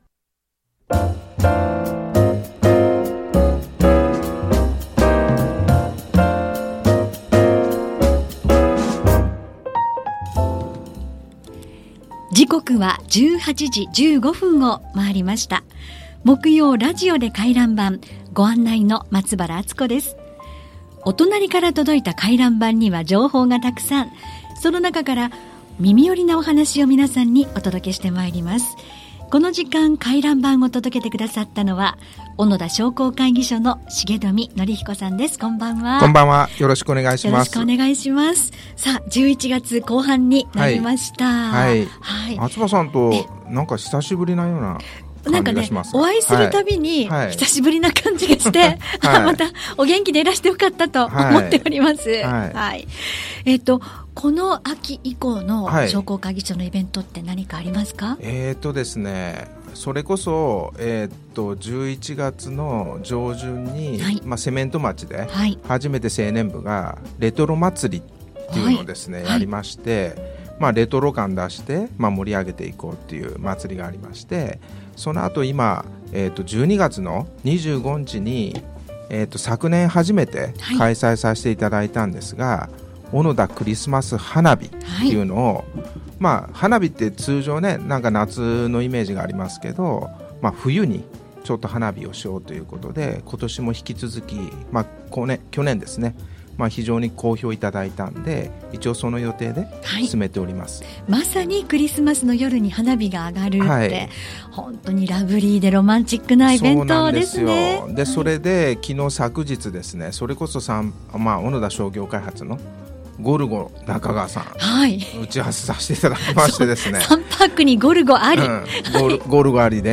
2025年11月20日「FMスマイルウェ～ブ」出演の放送をアップしました！